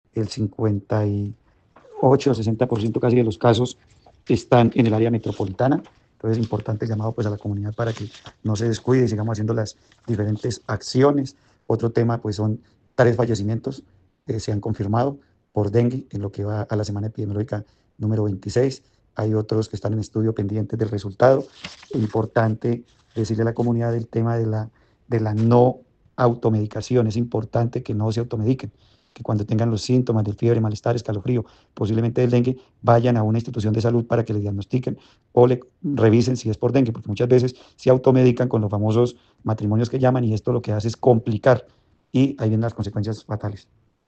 Edwin Antonio Prada Ramírez, secretario de Salud de Santander